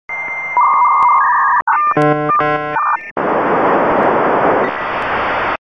I Still remember the acoustic sound the modem used to make to connect and my speed was 8Kbps.
modem sound.
modem-conn-snd.mp3